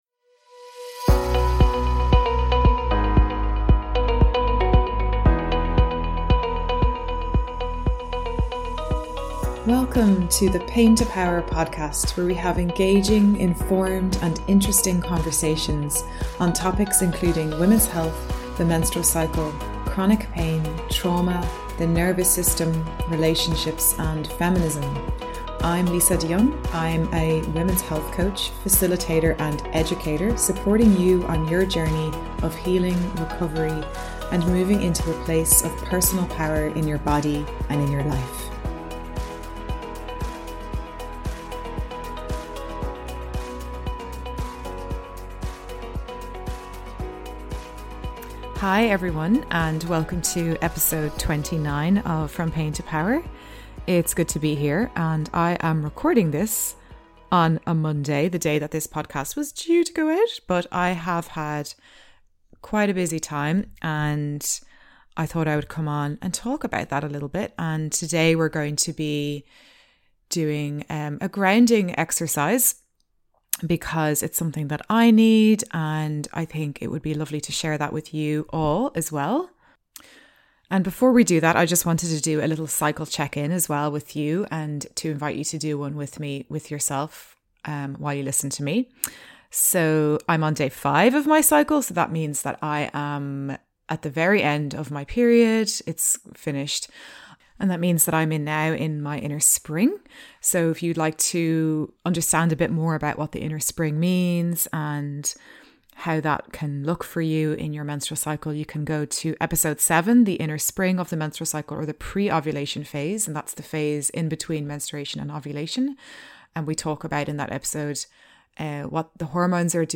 I would love to share with you live in this episode a simple guided grounding exercise that you can practice anytime and anywhere.
In this episode I guide a grounding meditation to support your nervous system to ground and come into the here and now.